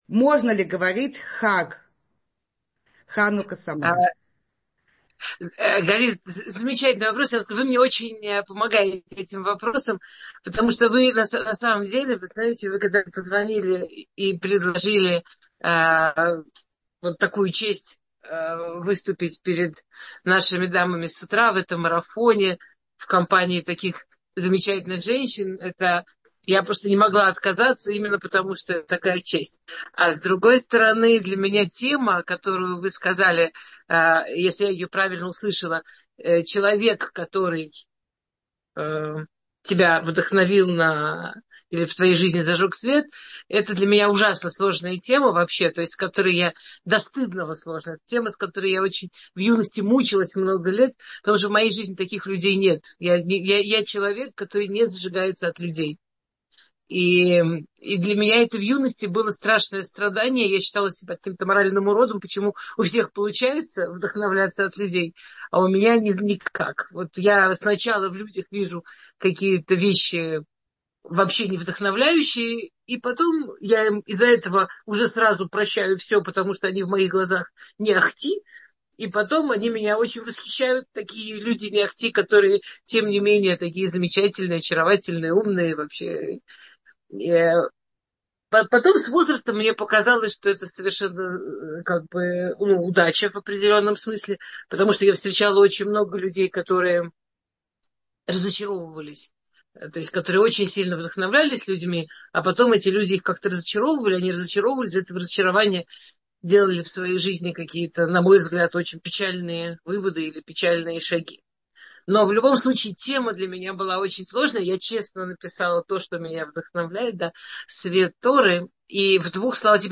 Утреннее Zoom ток-шоу «Утро с Толдот» приглашает вас на наш традиционный ханукальный Зум-марафон с кратким «спринт»-включением наших лекторов каждый день праздника.